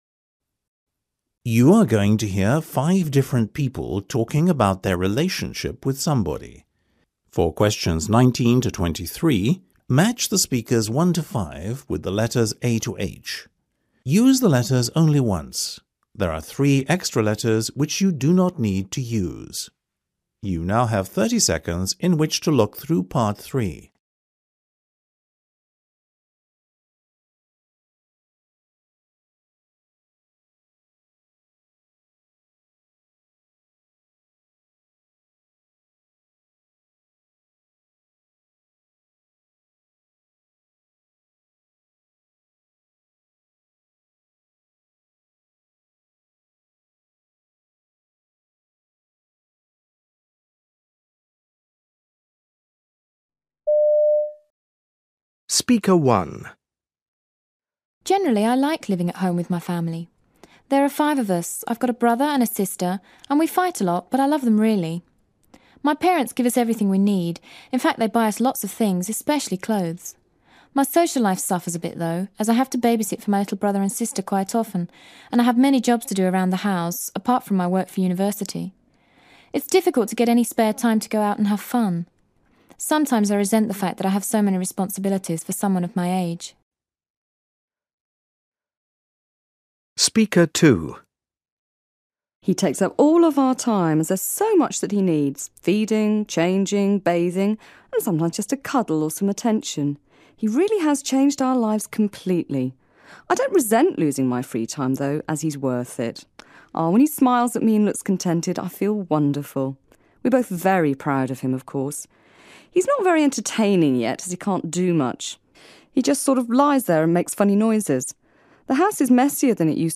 You are going to hear five different people talking about their relationship with somebody.